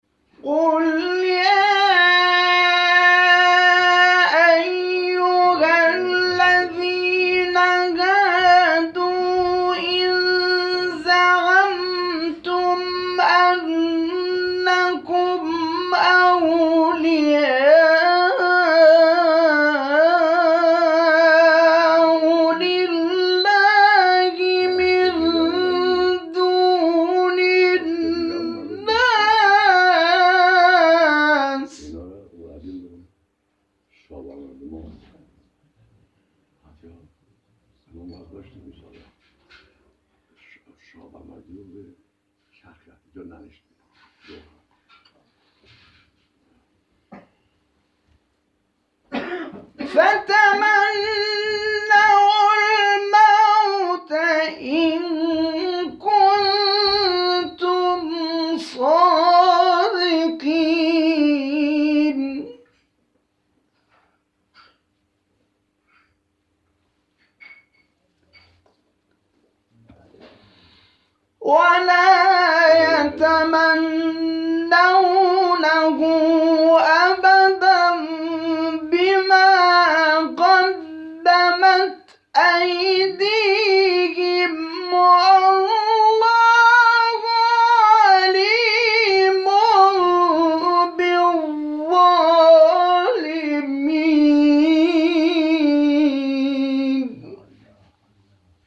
گروه فعالیت‌های قرآنی: فرازهای صوتی از قاریان ممتاز کشور ارائه می‌شود.